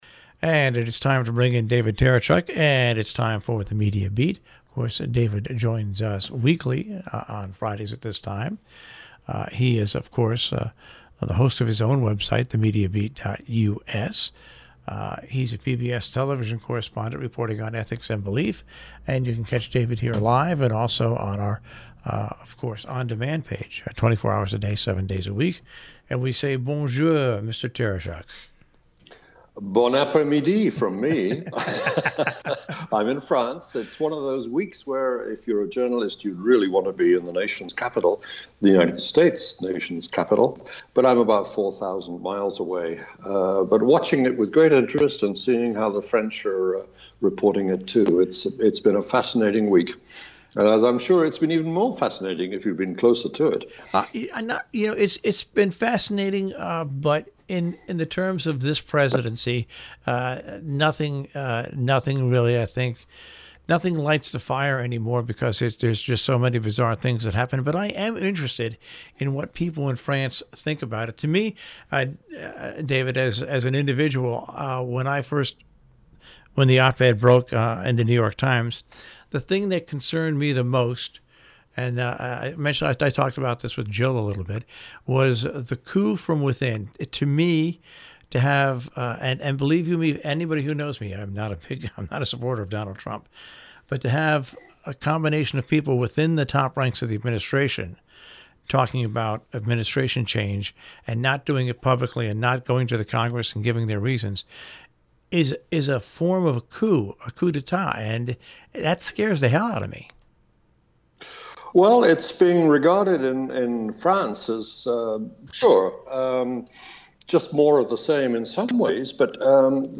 A radio version of THE MEDIA BEAT appears every week on the NPR Connecticut station WHDD – live on Friday morning and rebroadcast over the weekend.